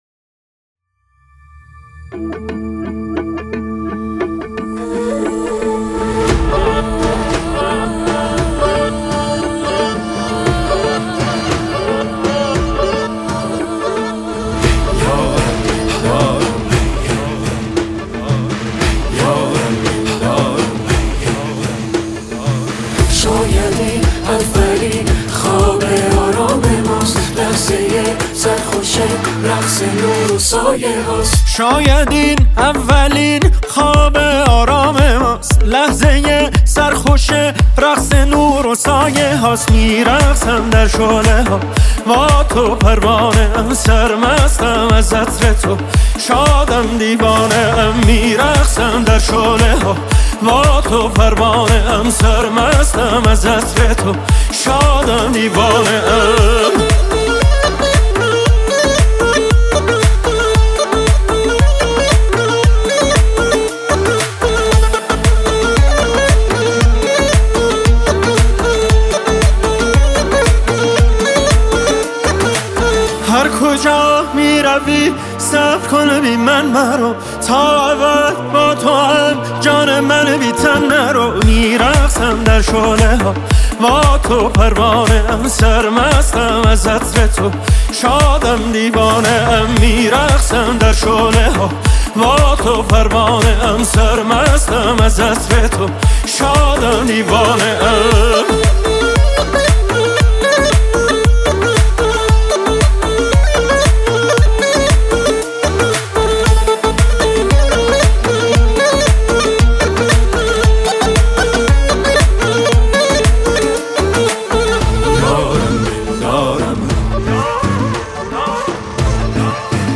شاد و سنتی